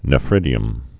(nə-frĭdē-əm)